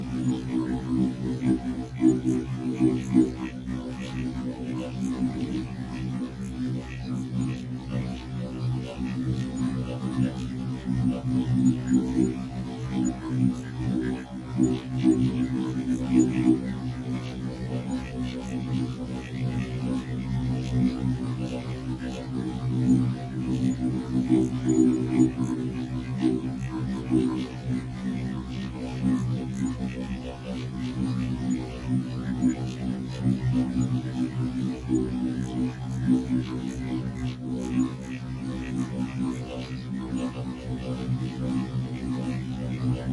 沸腾的泡沫 " 沸腾的泡沫3快
描述：一个小的记录，里面有一个煮着意大利面条的锅。
Tag: 酿造 气泡